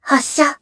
Kara-Vox_Attack1_jpb.wav